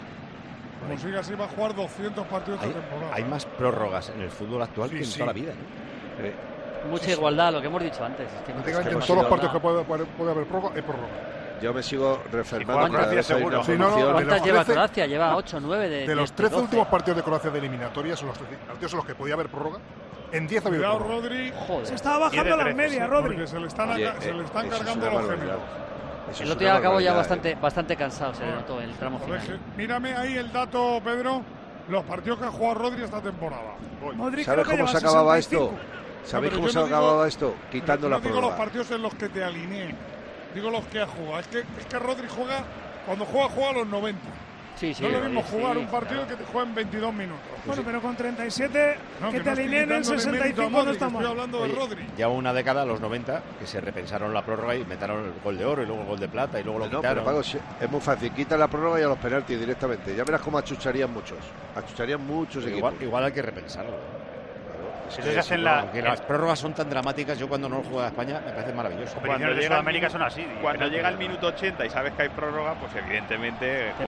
Antes de los penaltis, el director de Tiempo de Juego, Paco González, describió un detalle que le llamó la atención sobre lo que sucede ahora mismo en el fútbol europeo, y que ocurrió durante el Croacia-España.
Si quieres saber cuál es la reflexión de Paco González sobre la norma que cambiaría del fútbol, escucha el fragmento de Tiempo de Juego.